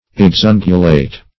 Meaning of exungulate. exungulate synonyms, pronunciation, spelling and more from Free Dictionary.
Search Result for " exungulate" : The Collaborative International Dictionary of English v.0.48: Exungulate \Ex*un"gu*late\, v. t. [imp.